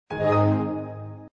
low_high_chord.mp3